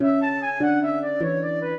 flute-harp
minuet6-10.wav